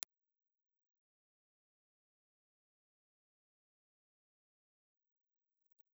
Impulse Response file of the RCA BK-5B ribbon microphone in position V2
RCA_BK5_V2_IR.wav
A three-position bass roll-off switch labeled M (Music), V1, and V2 allows users to tailor the microphone’s low-frequency response for different recording scenarios, aiding in proximity effect management or reducing low-end rumble.